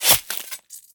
flesh1.ogg